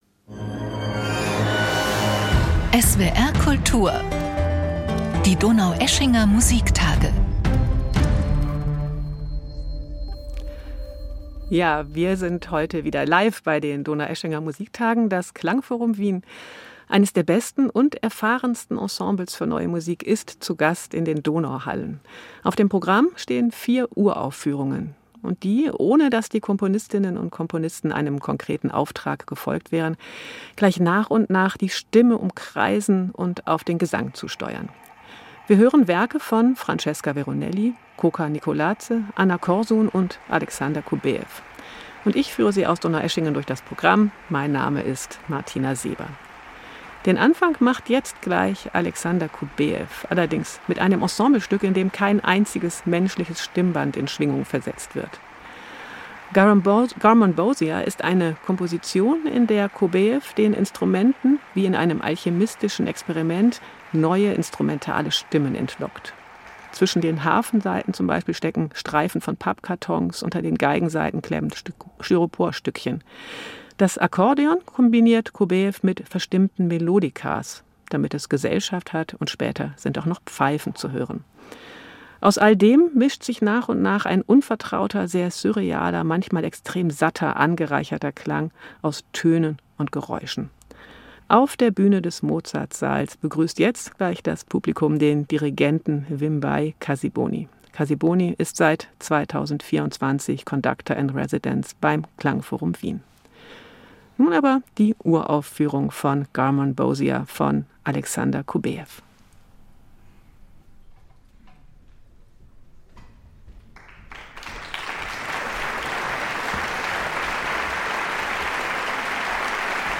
LIVE | 5.1-Surround